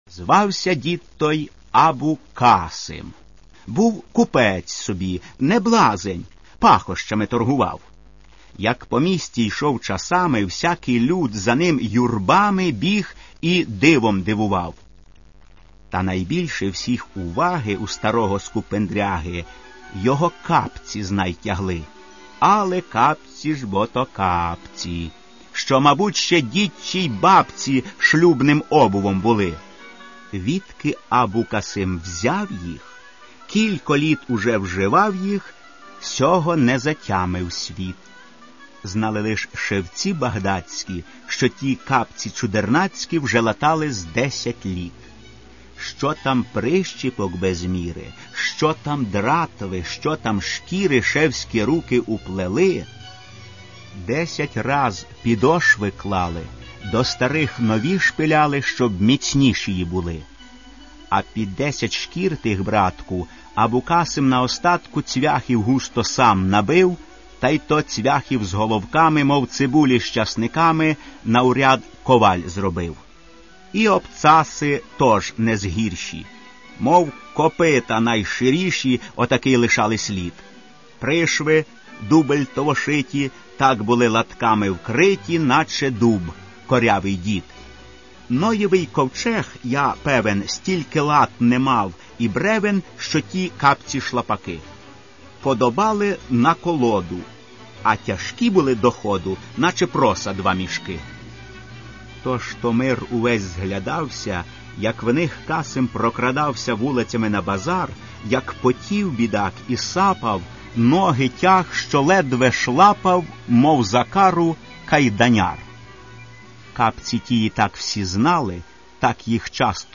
Каталог -> Аудіо книги -> Дитяча література